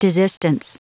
Prononciation du mot desistance en anglais (fichier audio)
Prononciation du mot : desistance